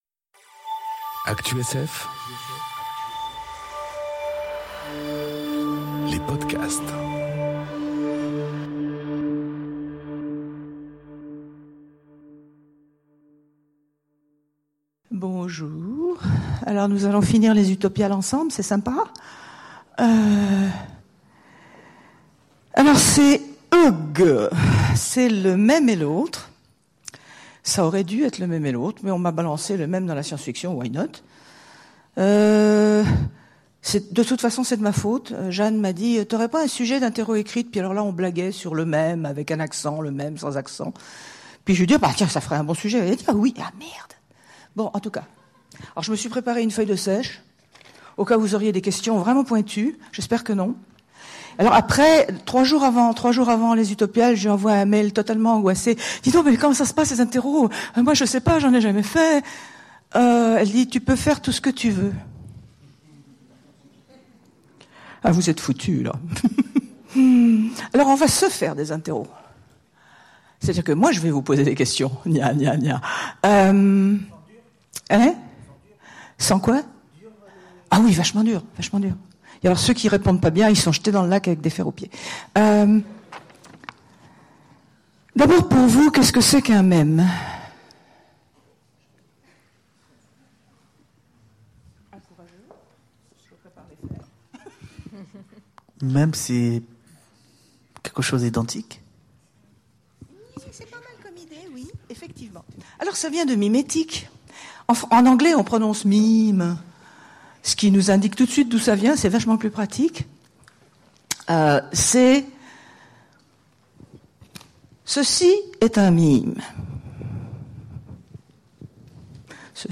Interro surprise Le Mème en science-fiction enregistrée aux Utopiales 2018